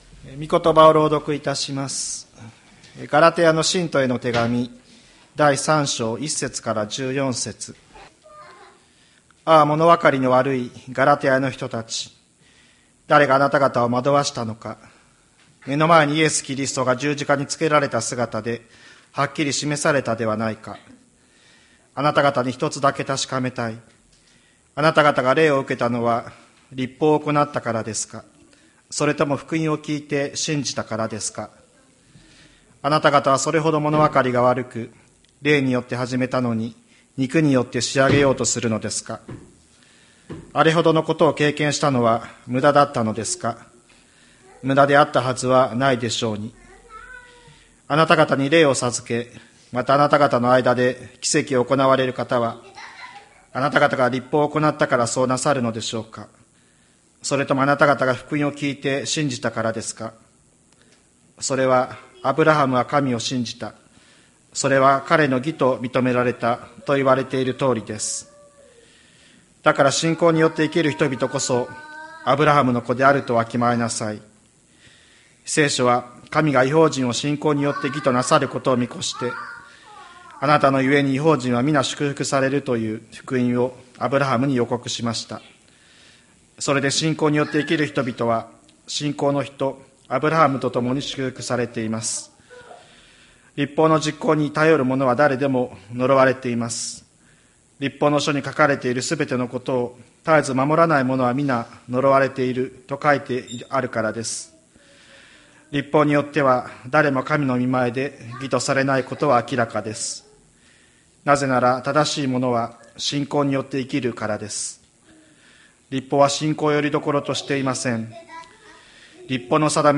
2024年03月17日朝の礼拝「わたしたちのための十字架」吹田市千里山のキリスト教会
千里山教会 2024年03月17日の礼拝メッセージ。